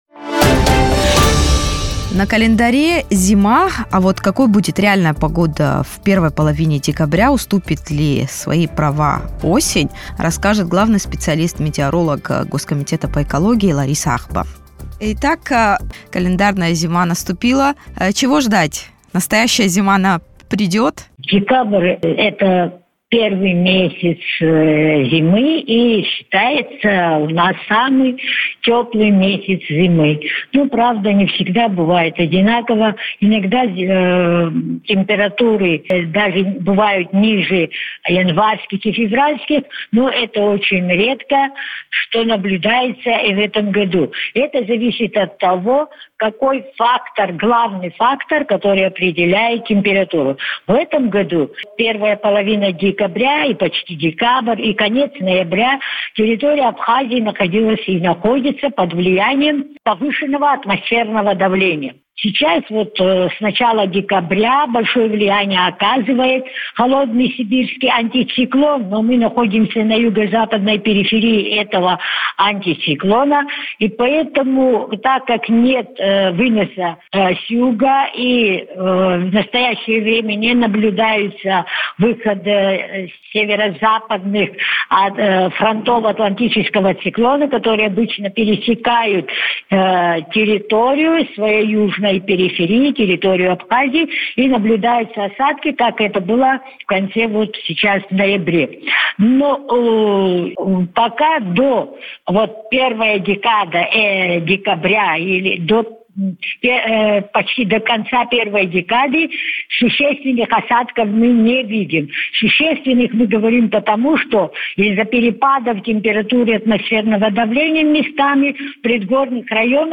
Радио